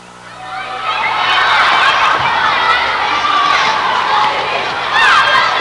Crowded Playground Sound Effect
Download a high-quality crowded playground sound effect.
crowded-playground.mp3